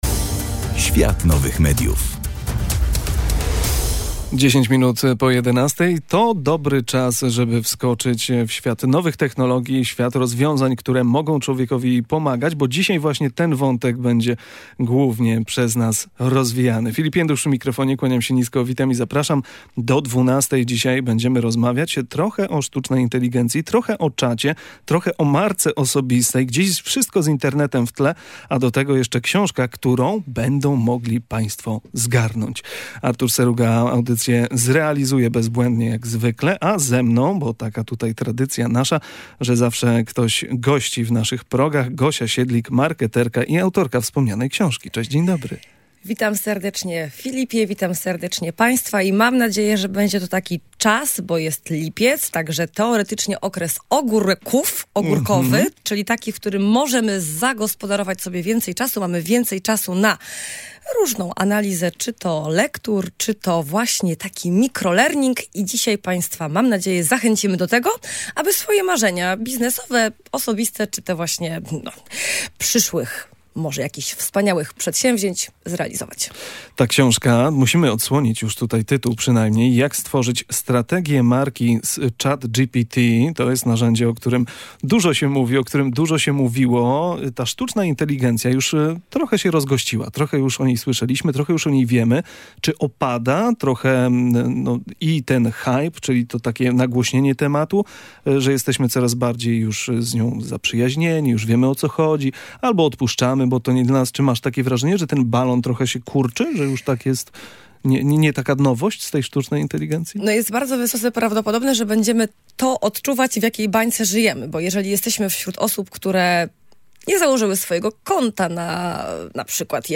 Kolejne wydanie audycji „Świat Nowych Mediów” to rozmowa o sztucznej inteligencji i tym jak można wplatać rozwiązania AI w swoją
Słuchacze opowiedzieli nam o swoich potrzebach od usprawnienia planu dnia, przez pomoc w pokonywaniu lęków (np. przed pływaniem), po automatyzację procesów zawodowych.